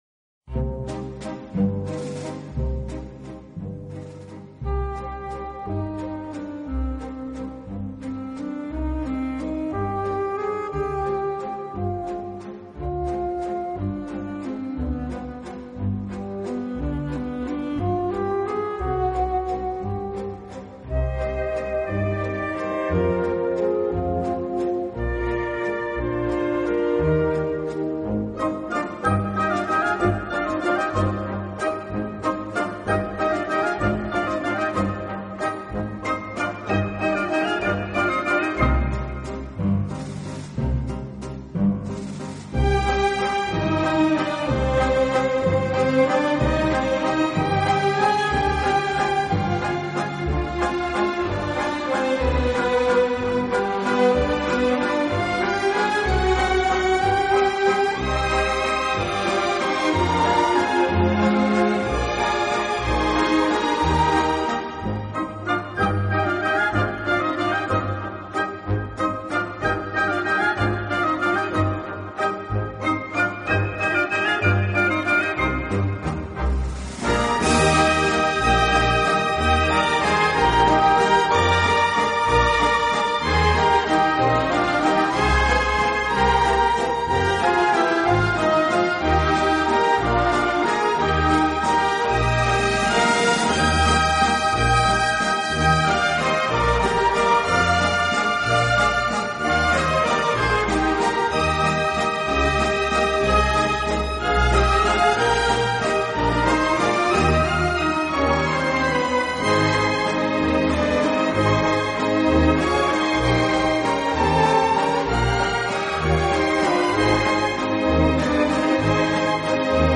类型：Class